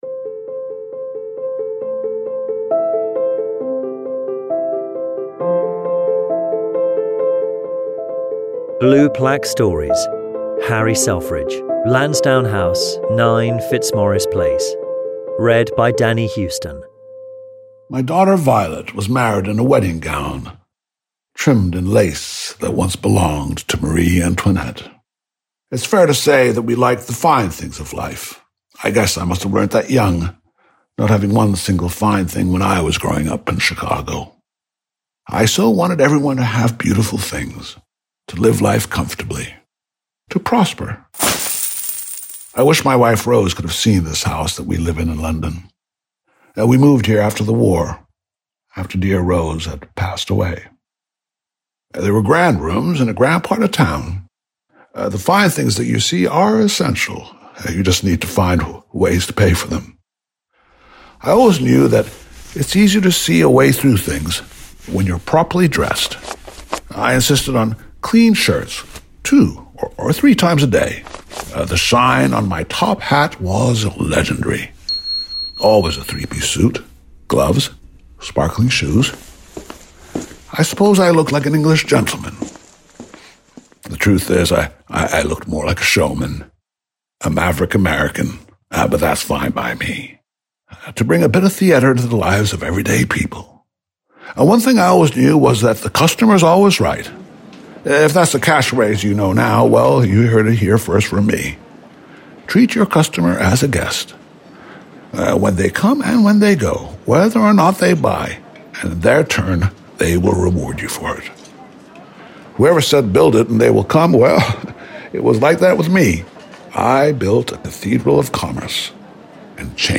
Harry Gordon Selfridge read by Danny Huston